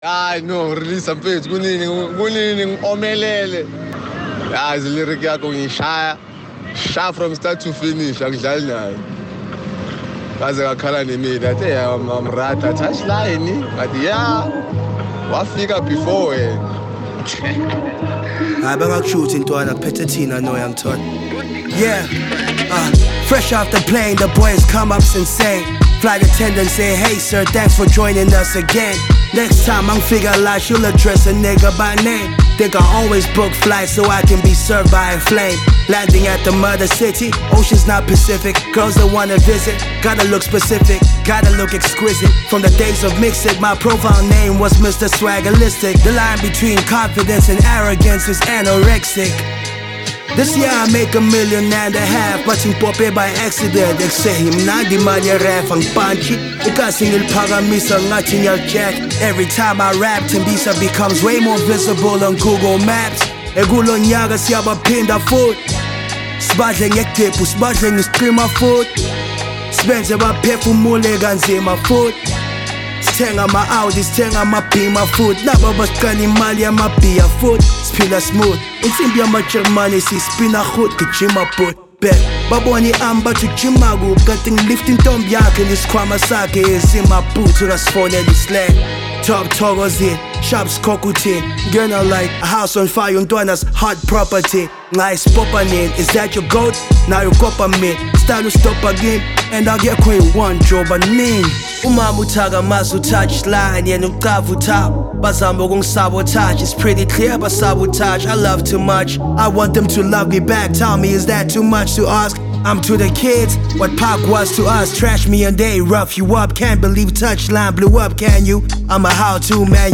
features soothing atmospheres and tunes